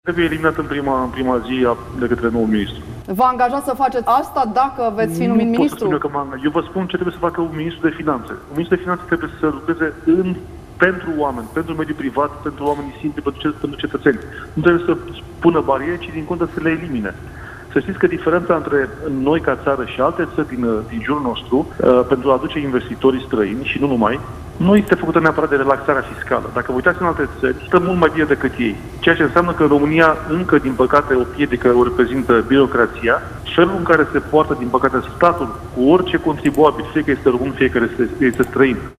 Preşedintele comisiei de buget- finanţe a Senatului, senatorul PSD Eugen Teodorovici, a explicat, într-o declarație la postul Antena 3, că statul trebuie să fie mult mai prietenos cu mediul de afaceri dar și cu persoanele fizice care constribuie la buget.